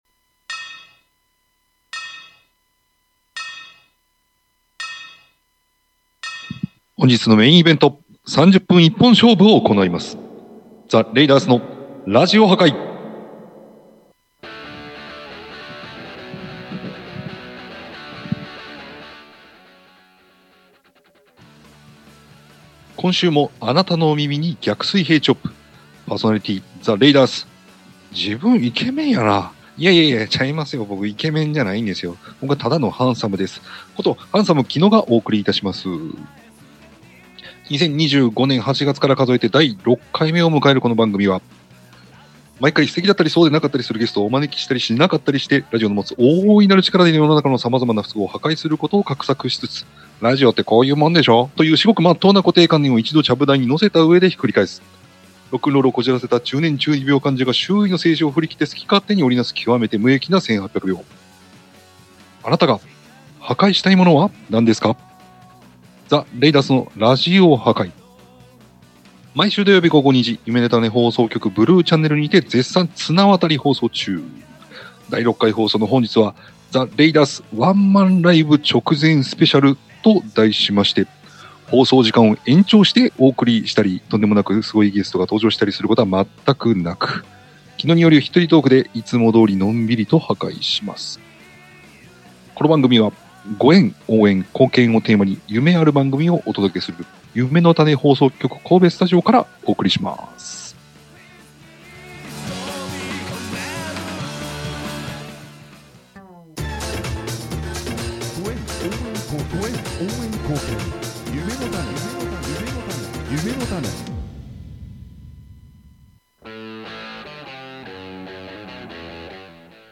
神戸を中心に活動するロックバンド、THE RAIDERSがお送りするロックなラジヲ番組